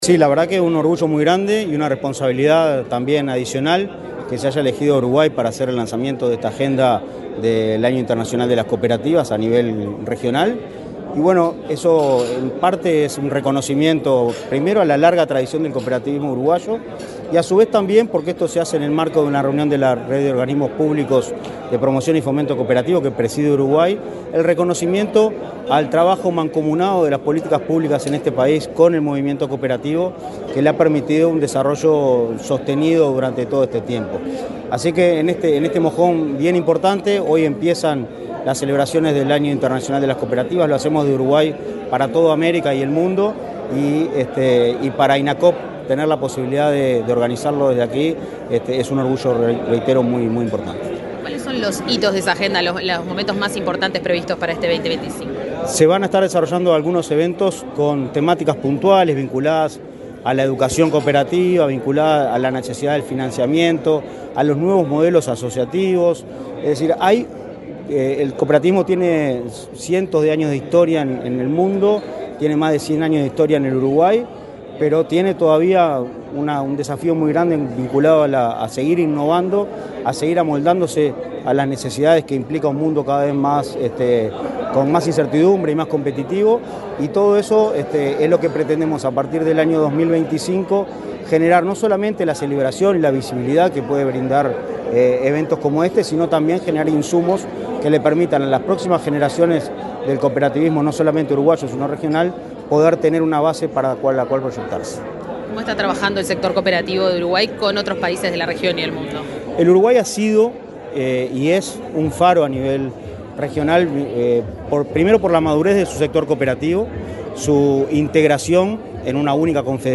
Entrevista al presidente de Inacoop, Martín Fernández
El presidente de Instituto Nacional de Cooperativismo (Inacoop), Martín Fernández, dialogó con Comunicación Presidencial, antes de participar en el